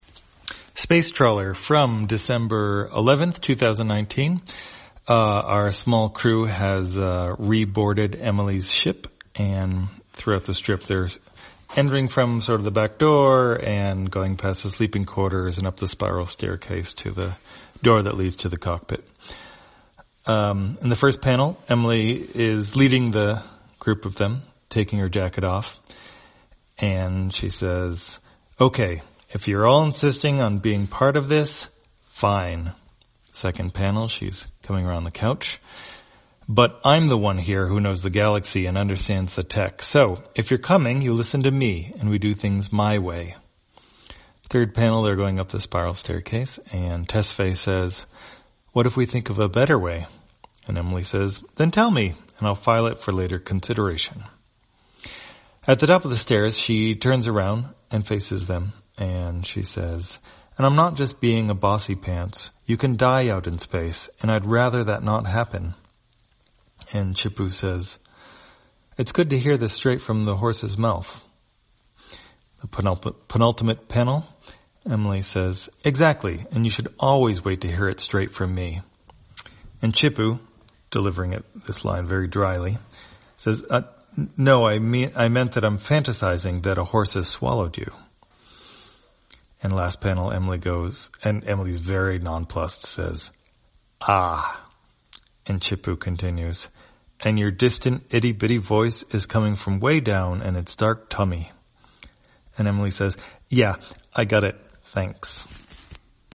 Spacetrawler, audio version For the blind or visually impaired, December 11, 2019.